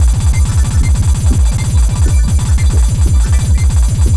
GABBER.wav